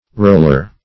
Roller \Roll"er\ (r[=o]l"[~e]r), n.